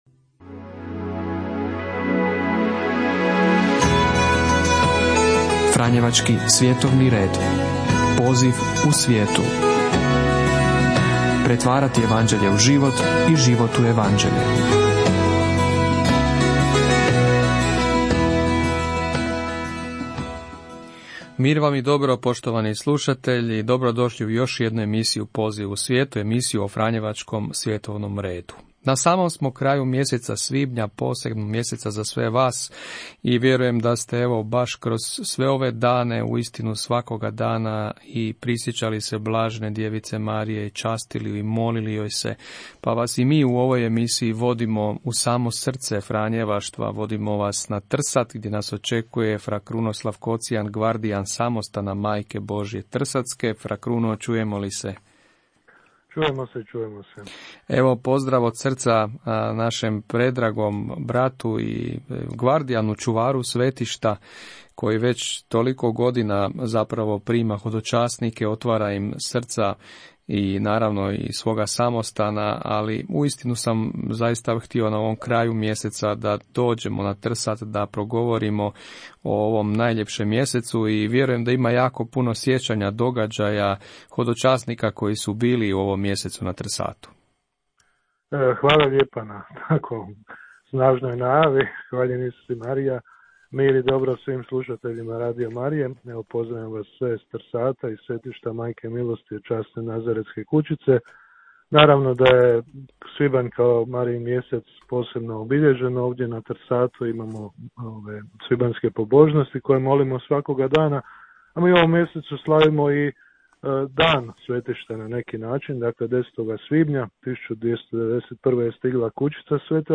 Snimka emisija “Poziv u svijetu” od 28. svibnja 2025.: